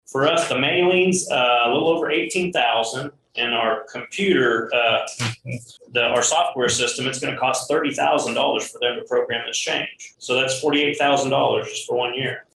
There’s also the expense of sending out mailers to the electorate, a cost incurred by taxing entities. Riley County Clerk Rich Vargo says the legislature has not appropriated any money for this, and anticipates the county incurring those costs.